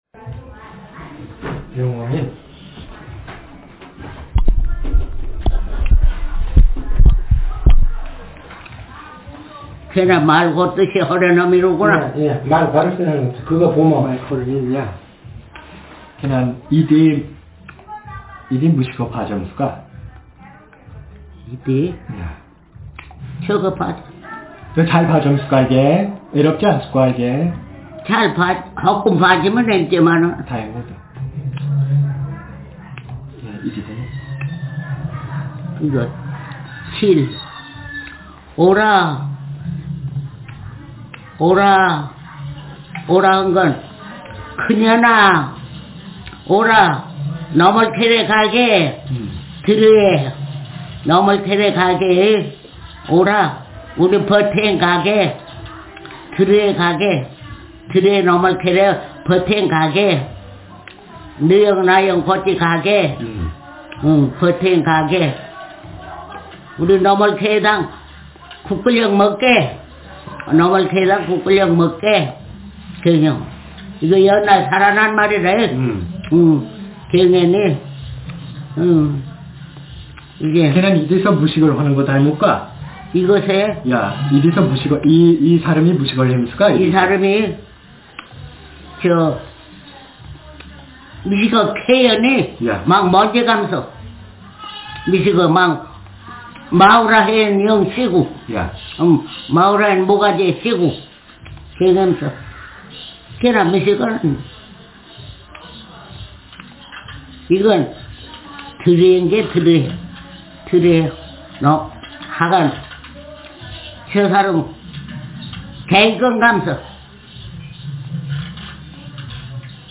Speaker sex f Text genre stimulus retelling